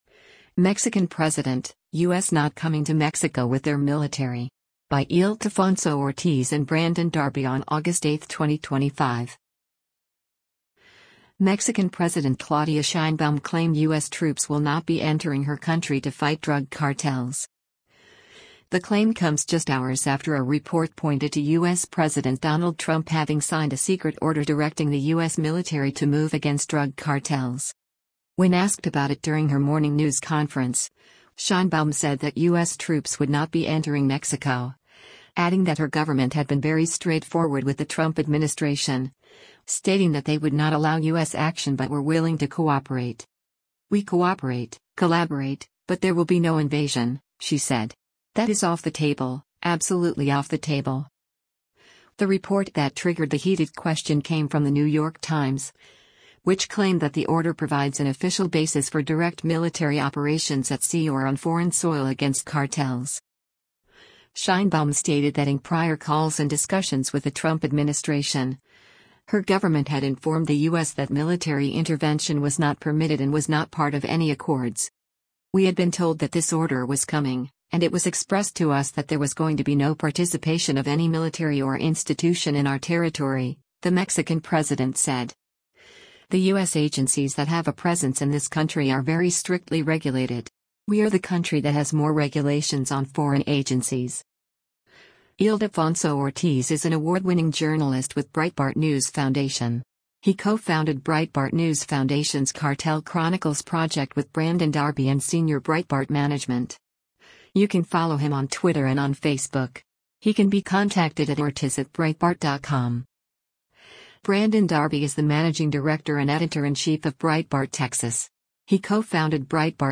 When asked about it during her morning news conference, Sheinbaum said that U.S. troops would not be entering Mexico, adding that her government had been very straightforward with the Trump administration, stating that they would not allow U.S. action but were willing to cooperate.